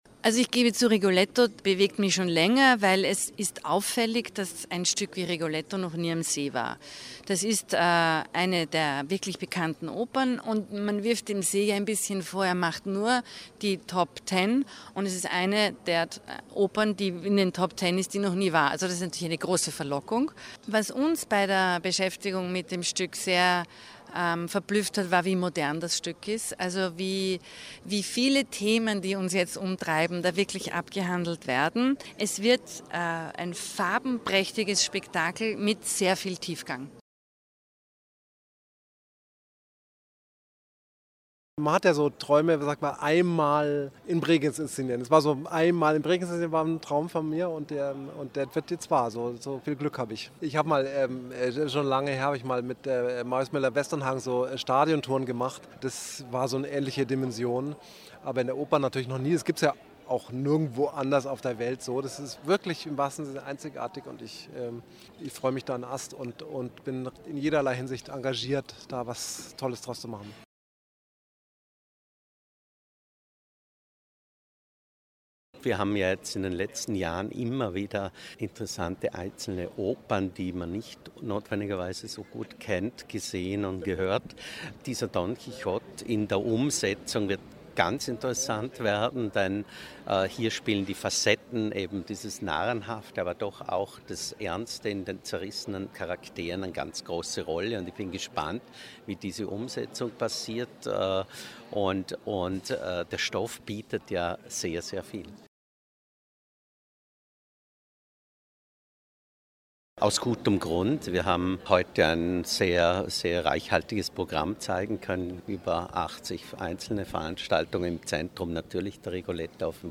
PK Festspielprogramm 2019 O-Töne features